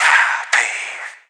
O VX 1.wav